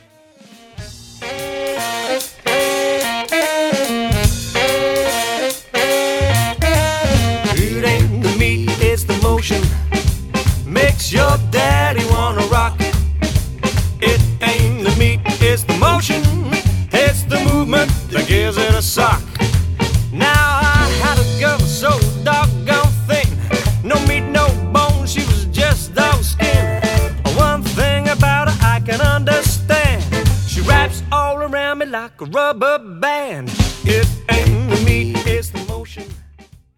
• Classic swing jazz and jive band
• Five-piece instrumental line-up
• Guitar, bass, drums, trumpet, sax, vocals